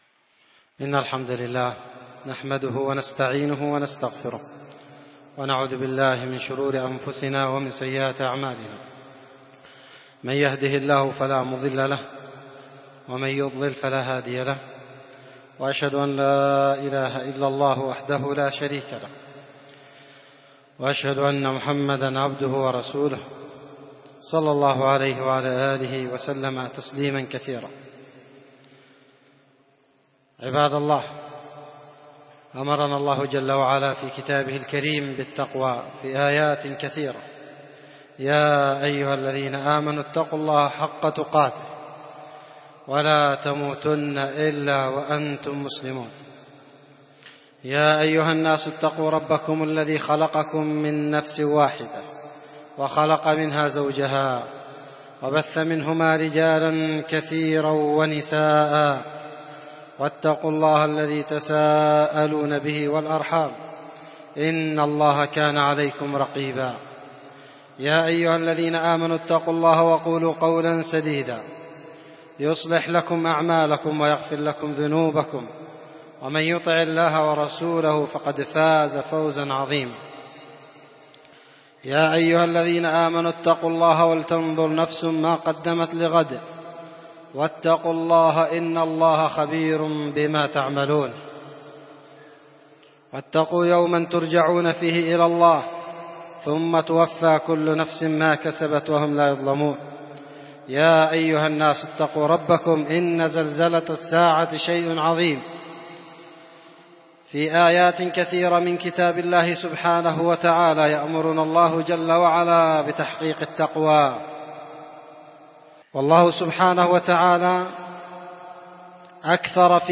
خطبه جمعه